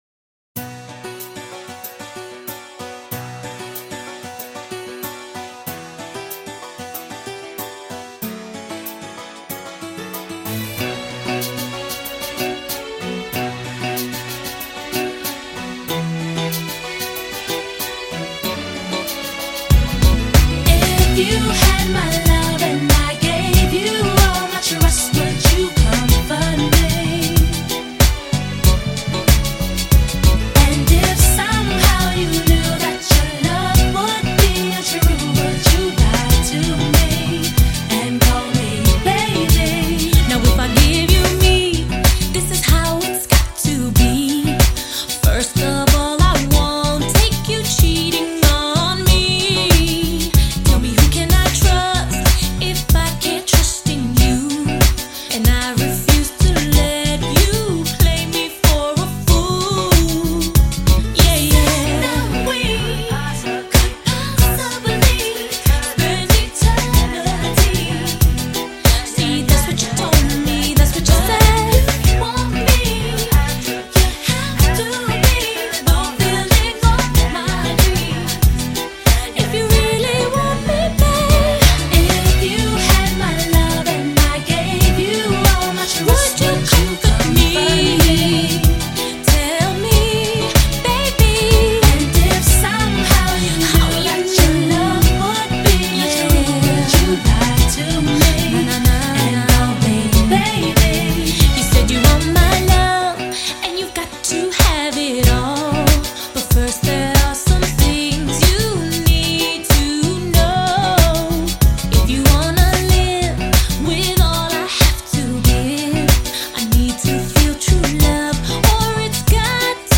Pop, R&B